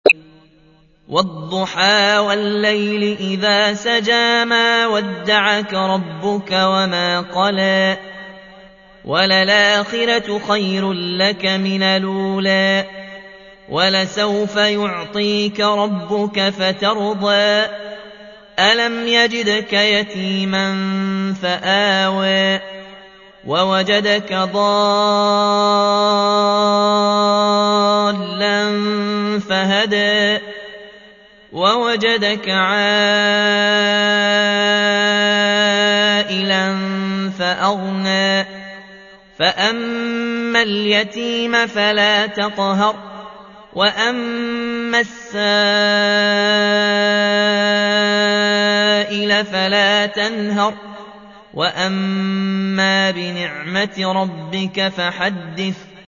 تحميل : 93. سورة الضحى / القارئ ياسين الجزائري / القرآن الكريم / موقع يا حسين